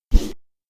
Melee Weapon Air Swing 2.wav